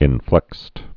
(ĭn-flĕkst)